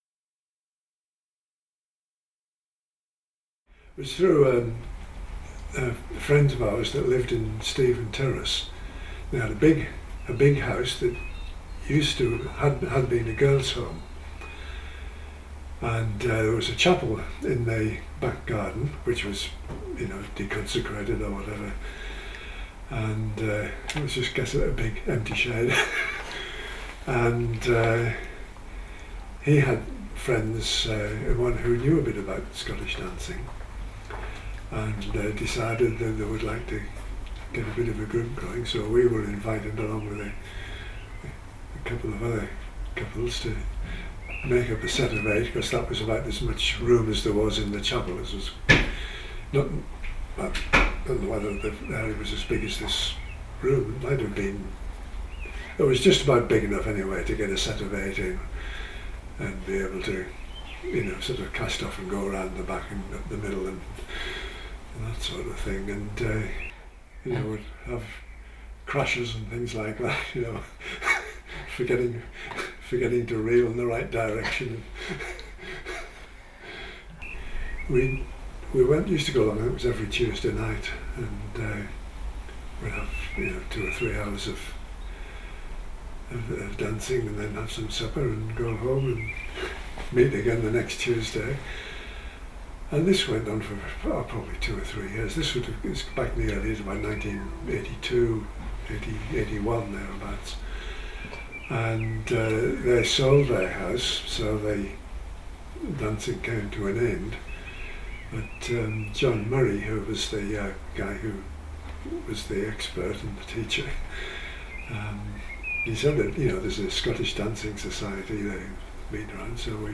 Video Interview